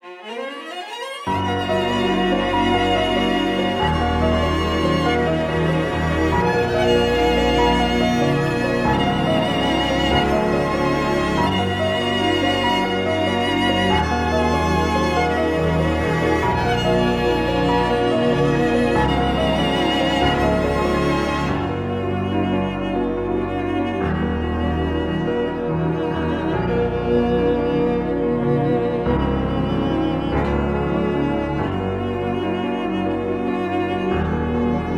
# Instrumental